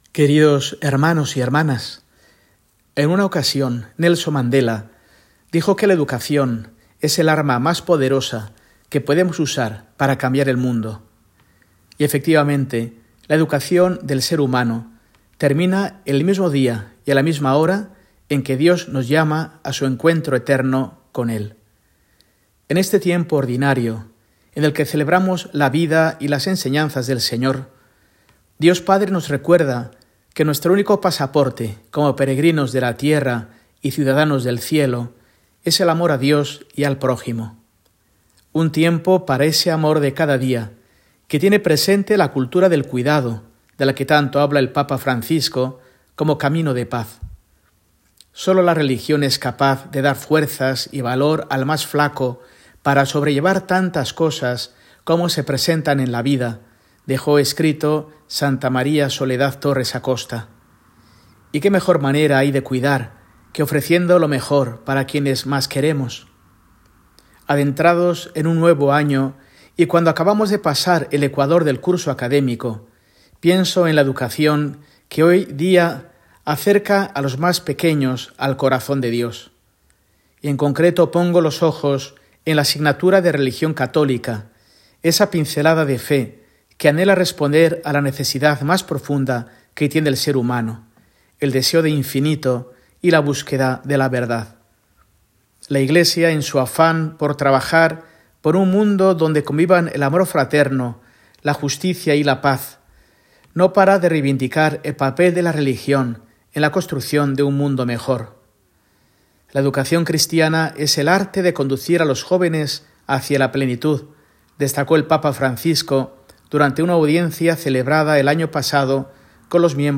Mensaje semanal de Mons. Mario Iceta Gavicagogeascoa, arzobispo de Burgos, para el domingo, 23 de febrero de 2025, VII del Tiempo Ordinario